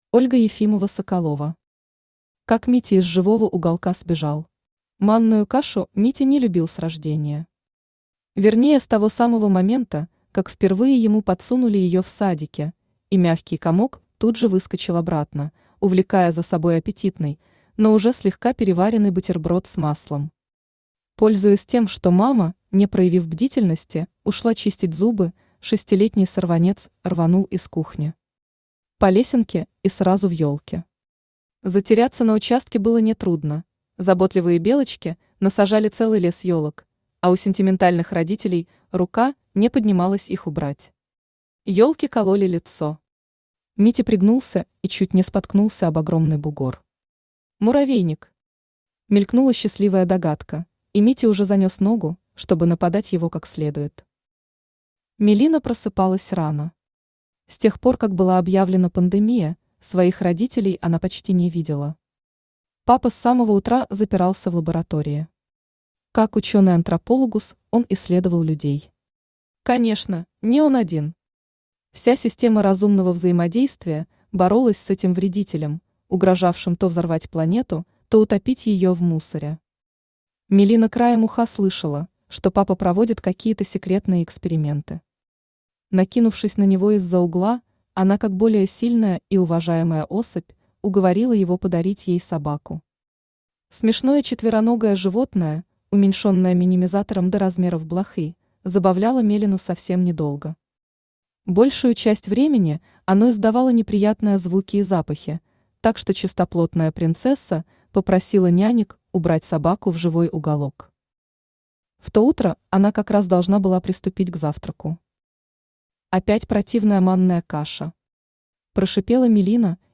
Аудиокнига Как Митя из живого уголка сбежал | Библиотека аудиокниг
Aудиокнига Как Митя из живого уголка сбежал Автор Ольга Ефимова-Соколова Читает аудиокнигу Авточтец ЛитРес.